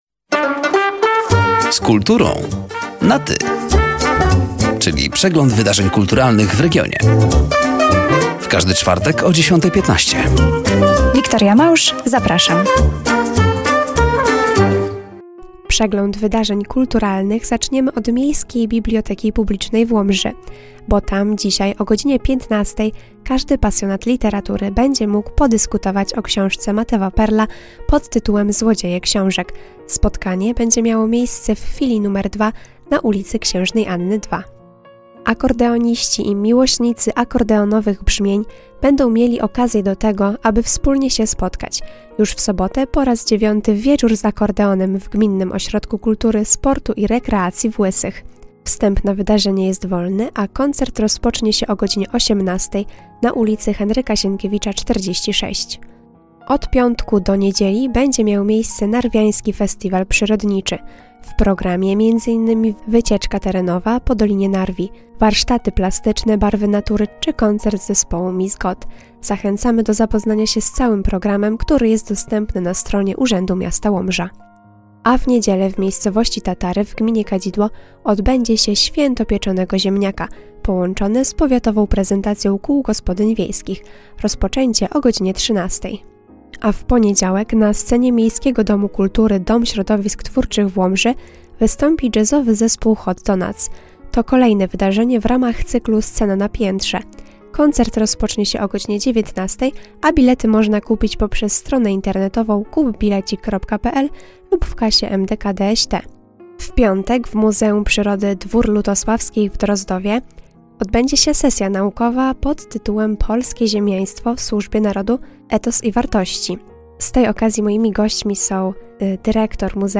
Zapraszamy do zapoznania się z innymi zbliżającymi się wydarzeniami oraz wysłuchania rozmowy.